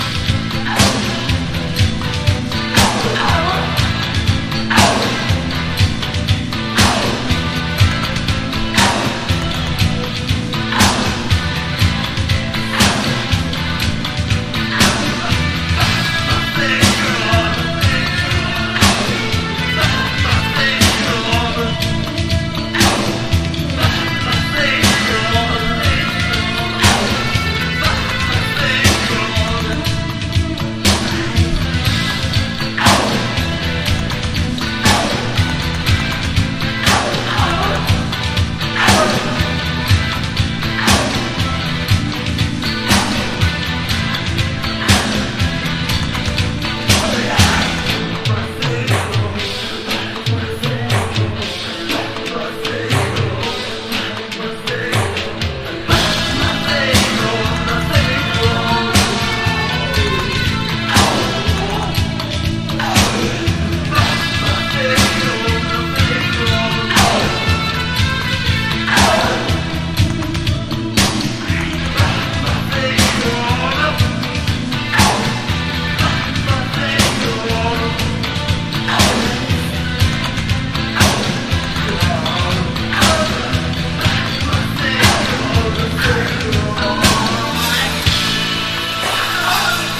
POST PUNK
PUNK / HARDCORE